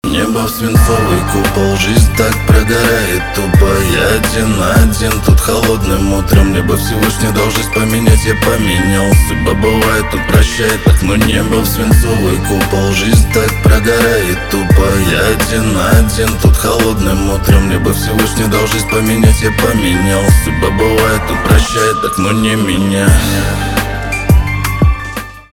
русский рэп
грустные , депрессивные , битовые , басы , пианино